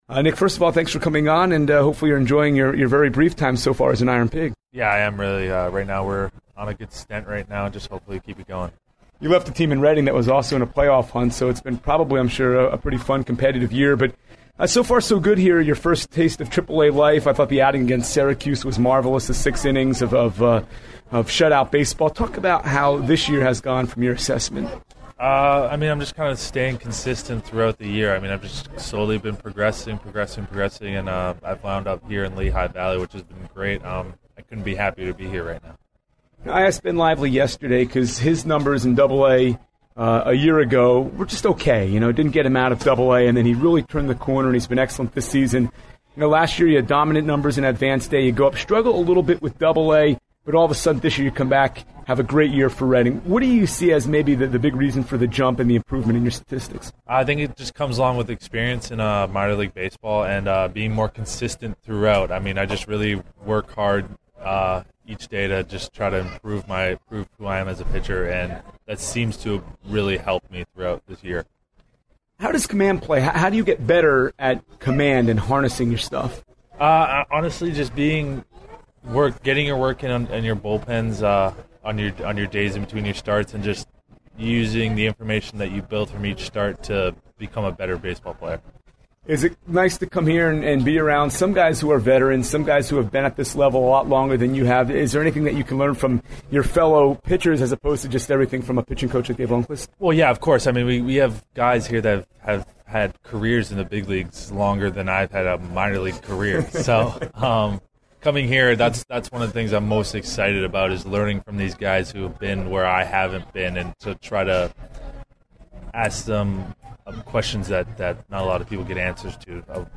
INTERVIEW with Pigs P Nick Pivetta